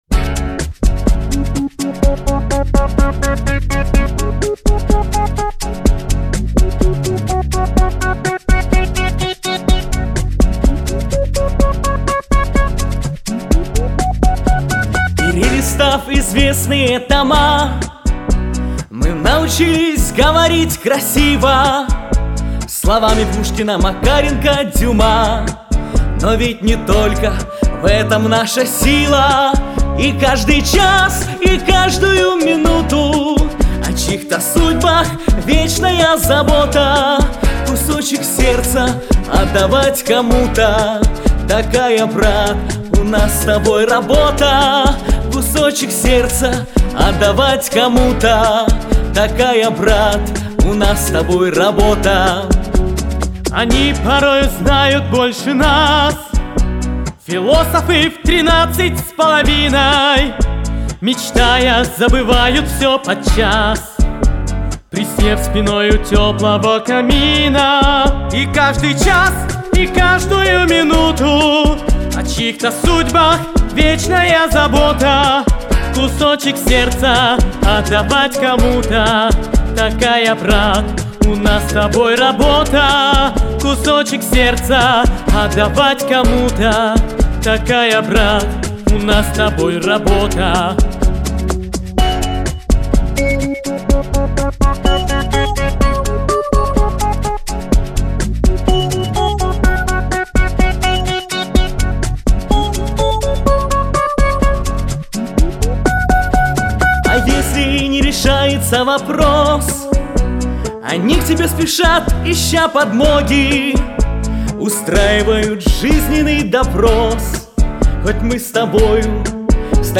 • Жанр: Детские песни
🎶 День учителя / Вожатские песни